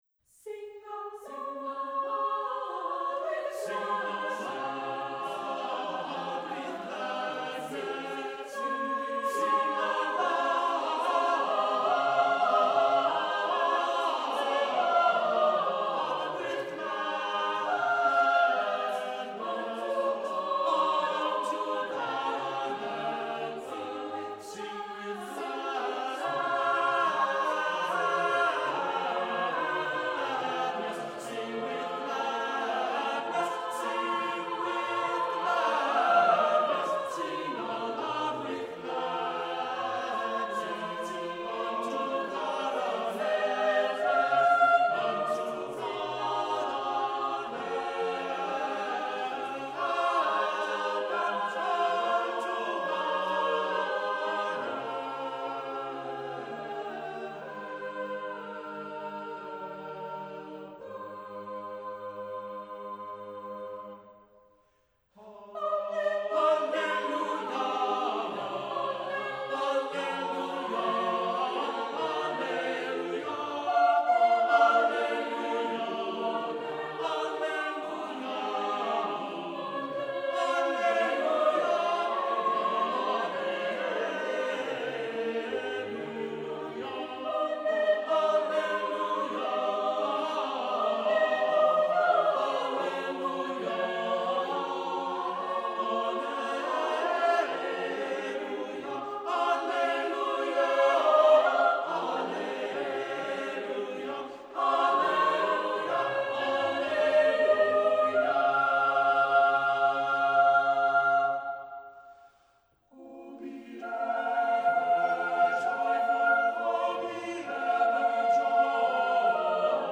Music Category:      Christian